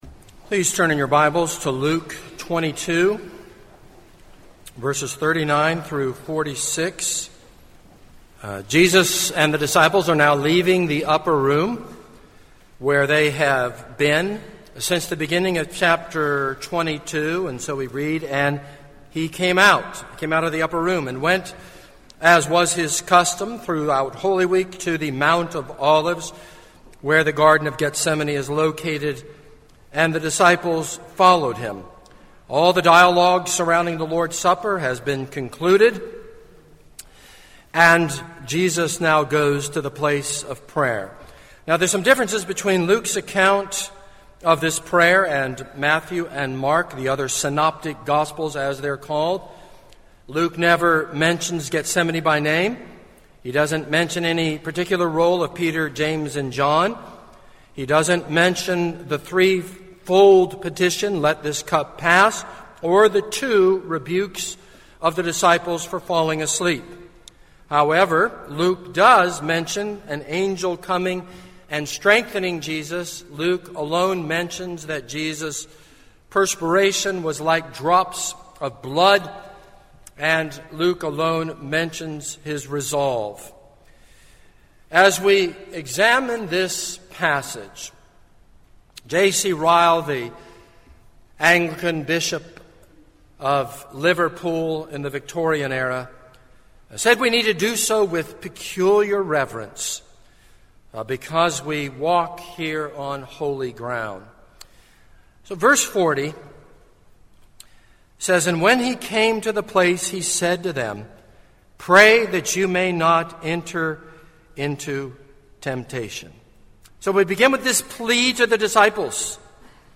This is a sermon on Luke 22:39-46.